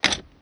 glove_compartment_closing.wav